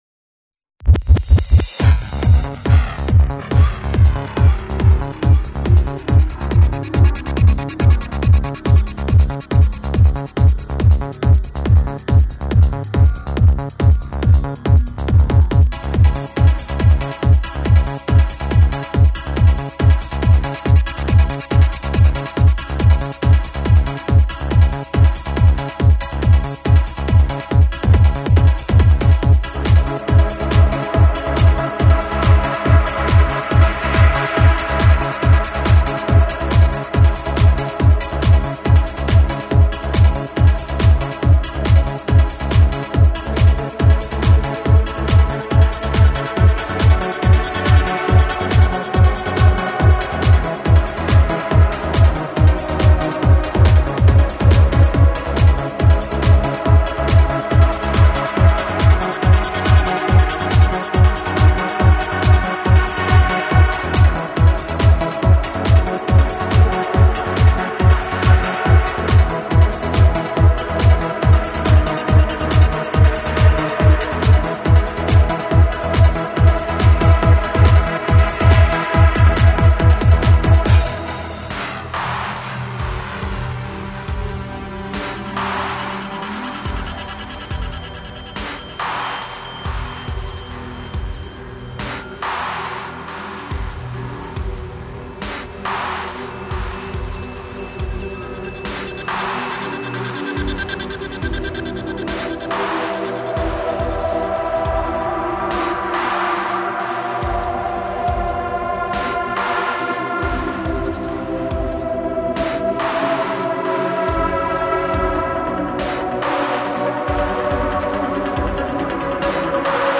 Выкладываю на Ваш суд ремикс данного произведения.